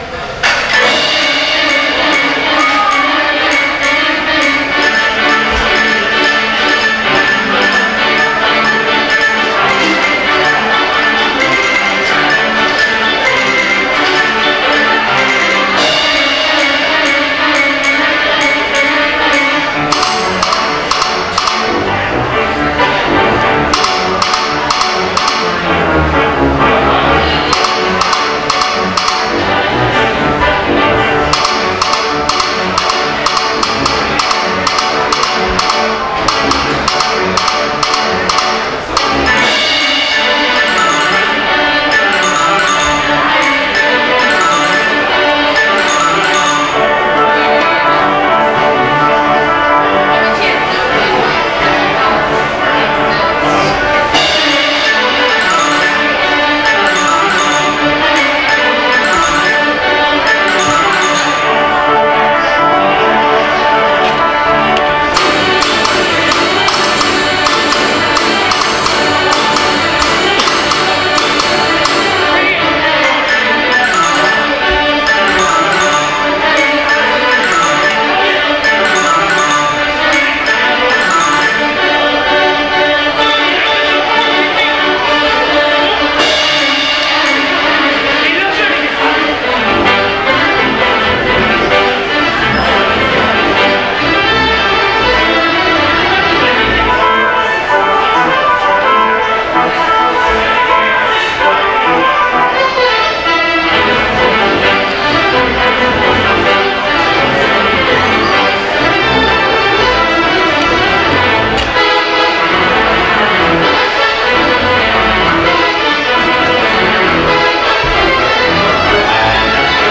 theater-organ recital